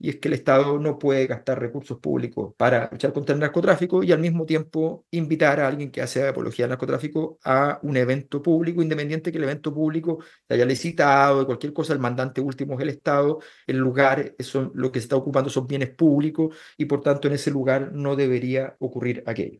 Esta fue parte de su intervención.